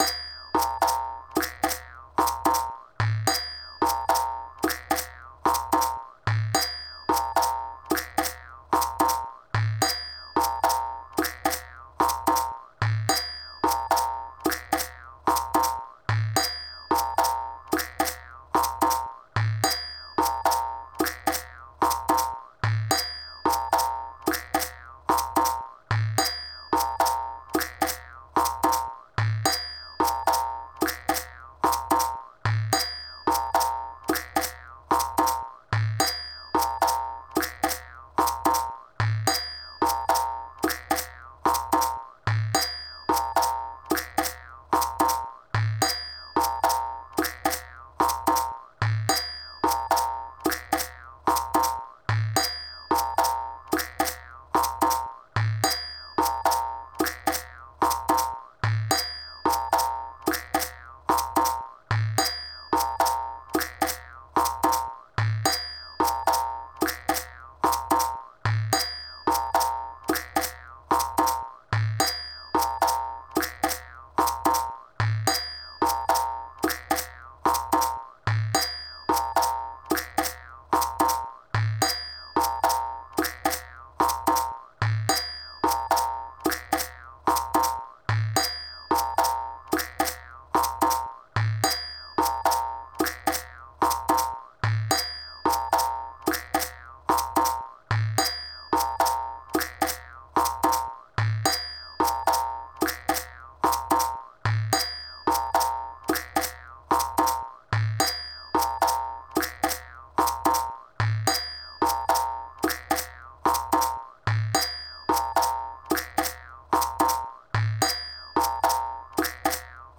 Percus Shuffle 1a.mp3